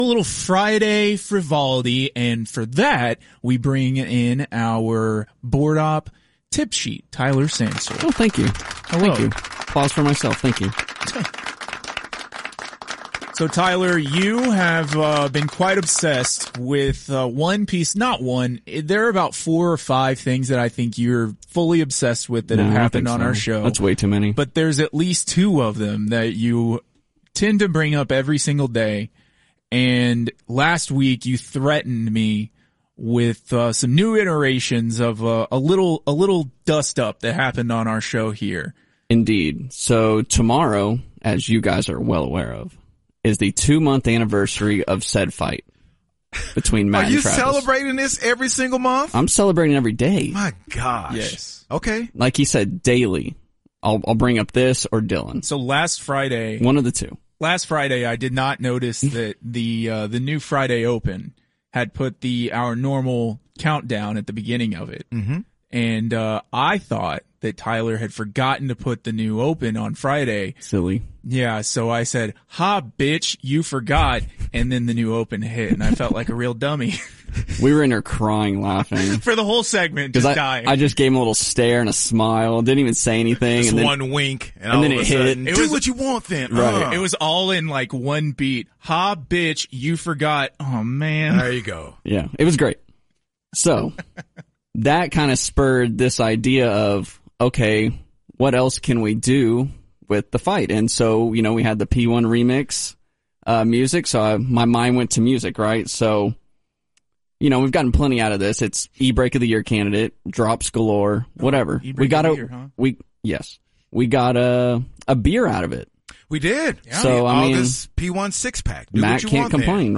Remixes